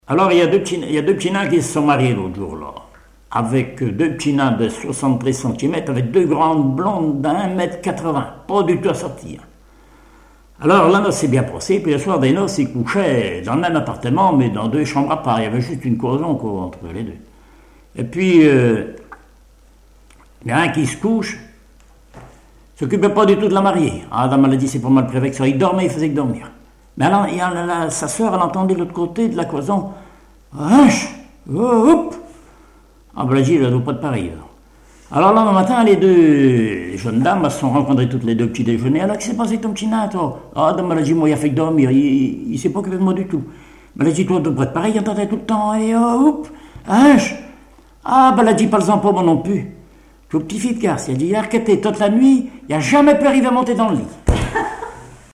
Genre sketch
Catégorie Récit